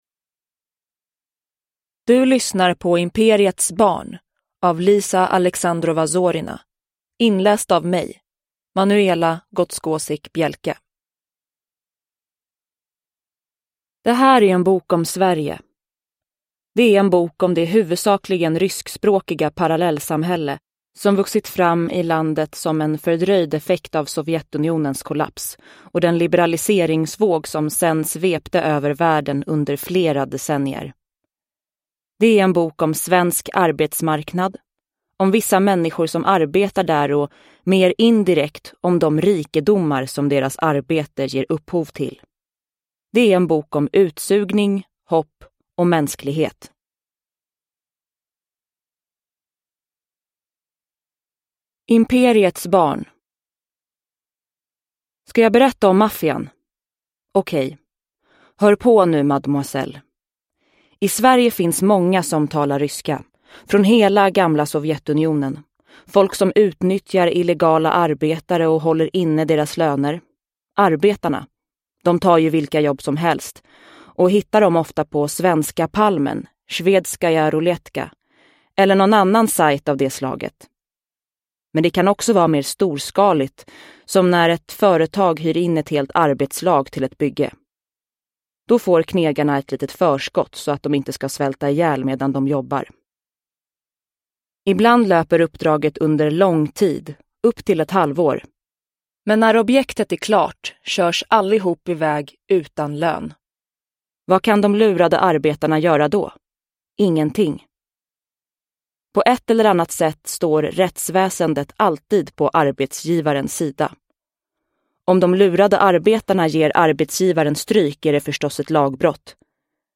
Imperiets barn : ett reportage – Ljudbok